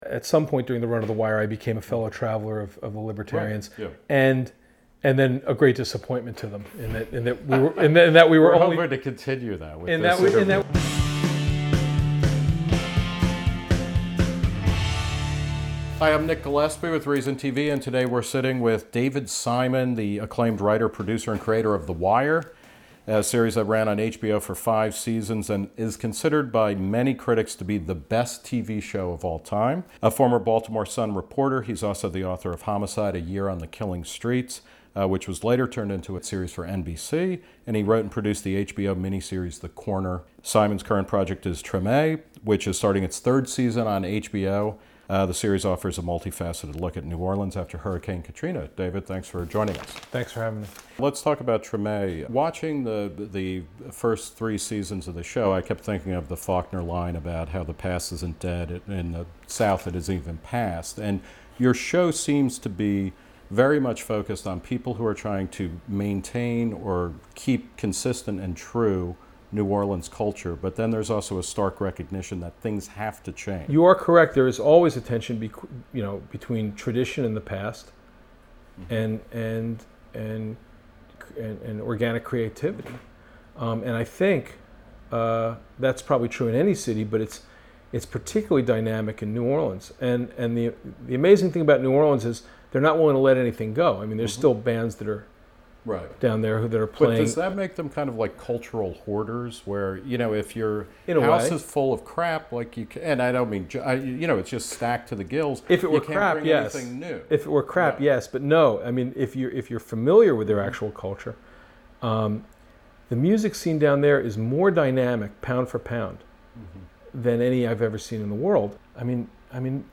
Updated September 21: On his personal website, David Simon has accused Reason TV of producing a "shanked" interview with him.